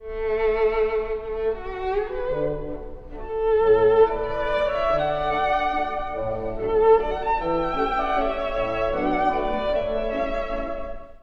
男爵はすっかり上機嫌になり、お得意のワルツを歌います。